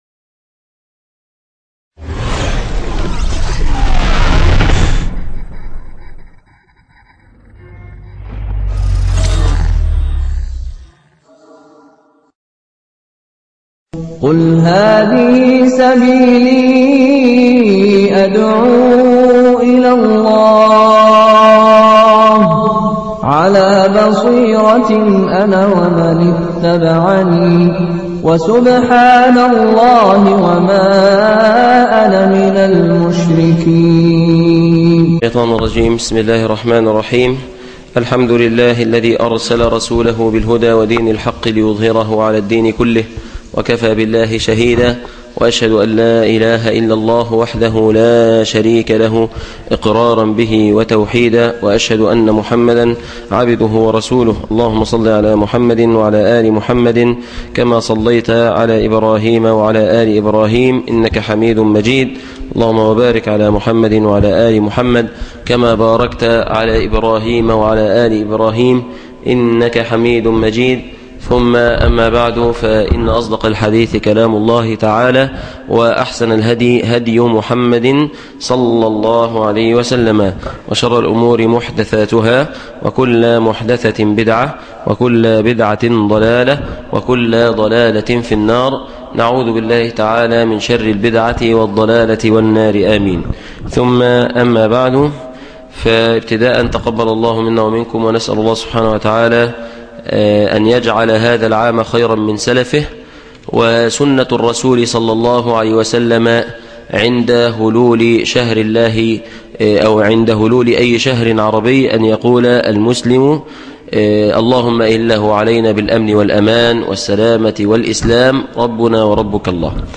خدعوك فقالوا 01 - الشريعة مطبقة !!!! درس